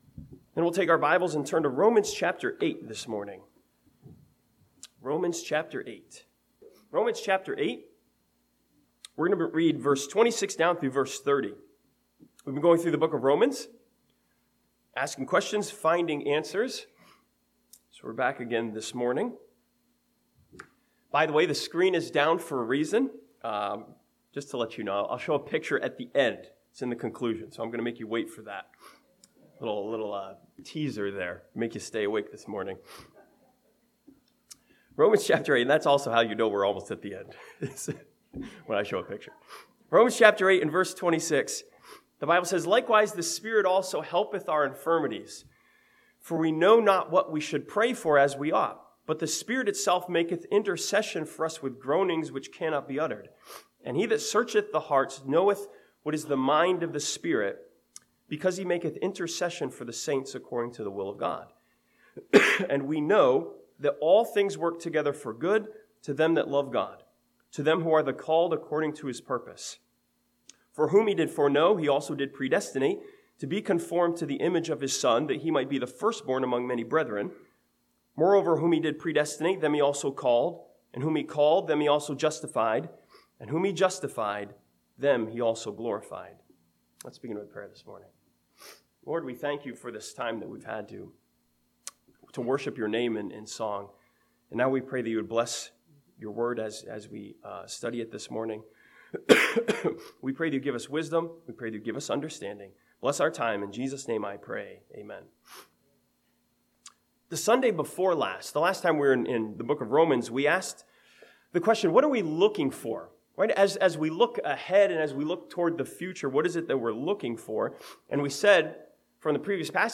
This sermon from Romans chapter 8 challenges us to examine what exactly we are searching for and if it is in line with God's will.